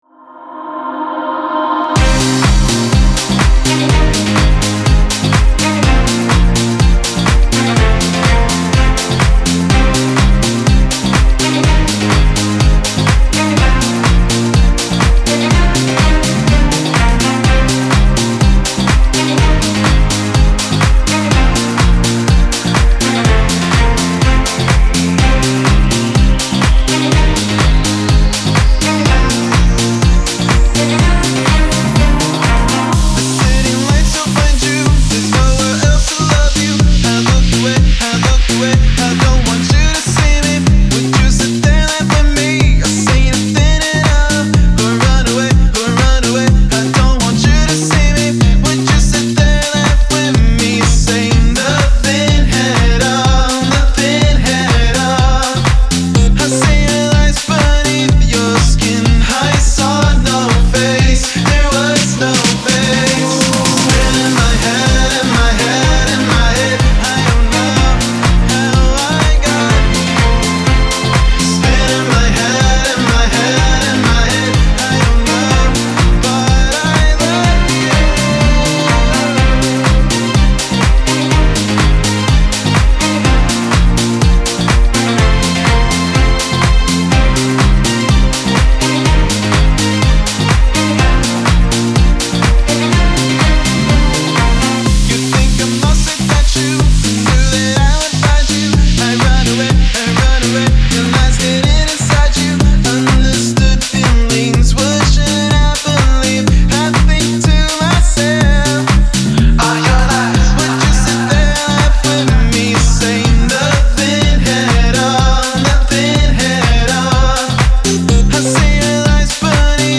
House Radio